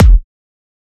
edm-kick-46.wav